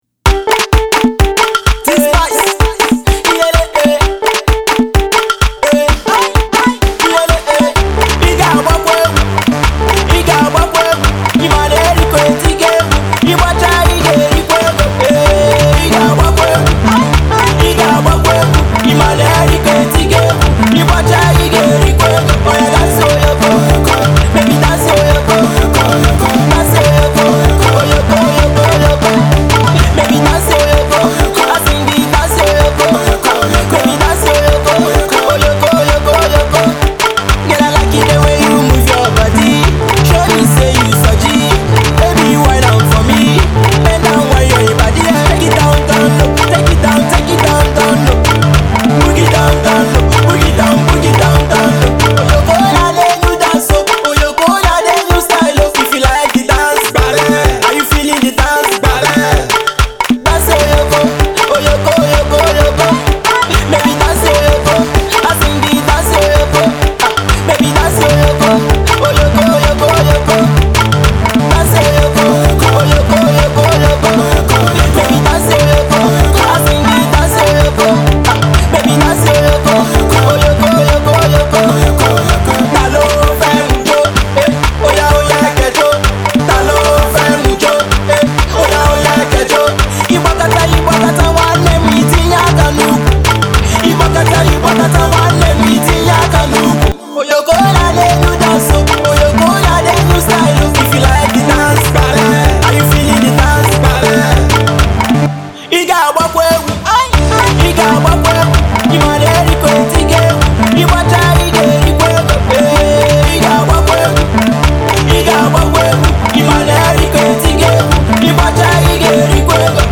it will light up a few dance floors